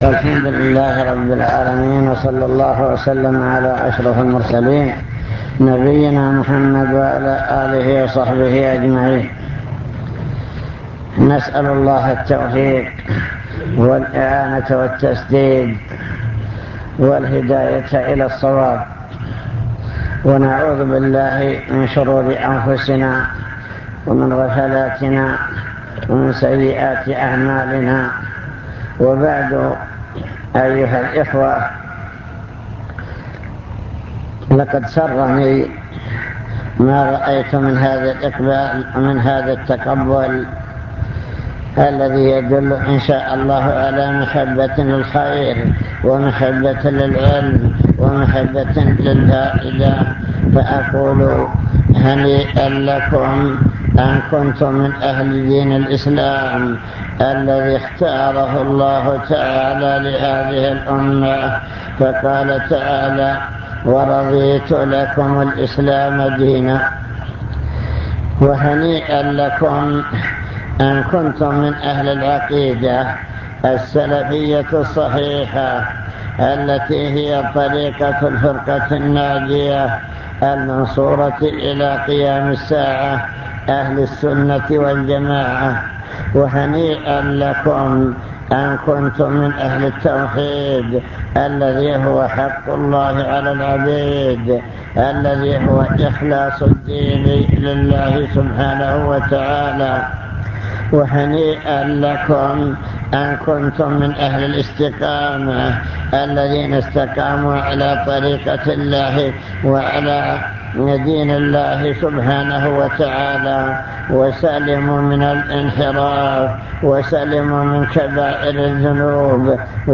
المكتبة الصوتية  تسجيلات - محاضرات ودروس  محاضرة بعنوان من يرد الله به خيرا يفقهه في الدين